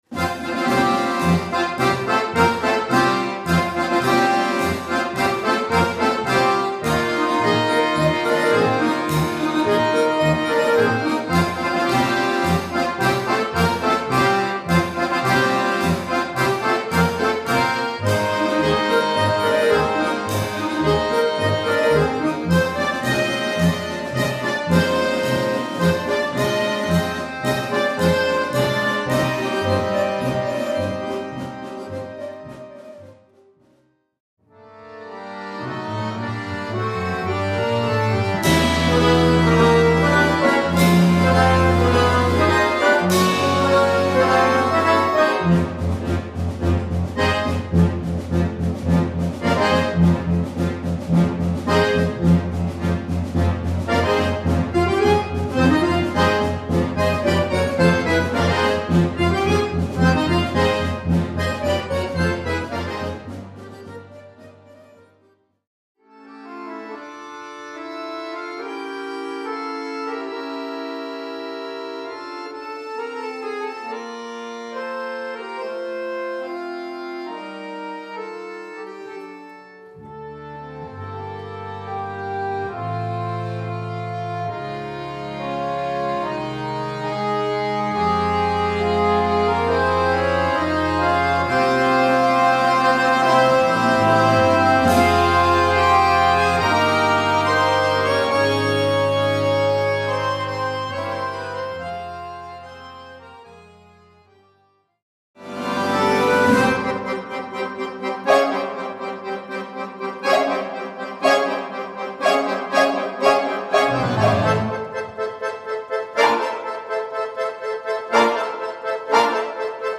Répertoire pour Accordéon - Orchestre d'Accordéons